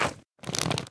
Minecraft Version Minecraft Version 25w18a Latest Release | Latest Snapshot 25w18a / assets / minecraft / sounds / mob / magmacube / jump1.ogg Compare With Compare With Latest Release | Latest Snapshot
jump1.ogg